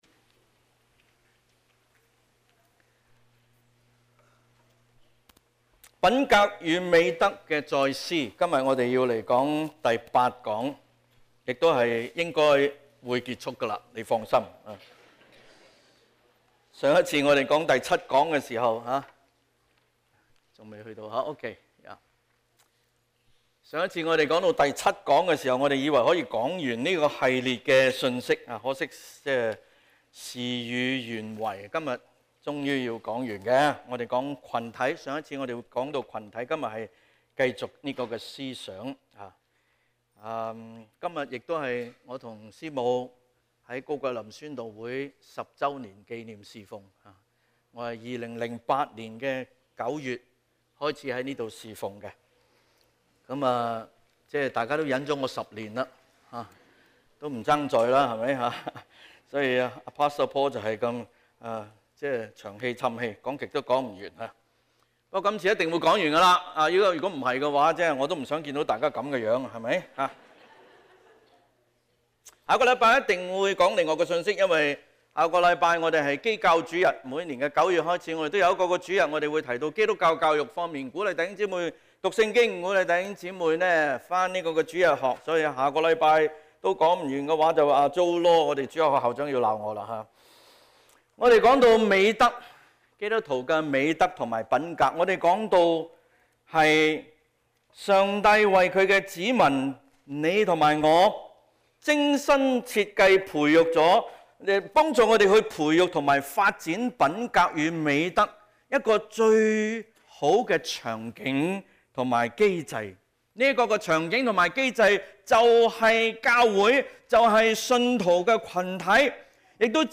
SERMONS | 講道 | Westwood Alliance Church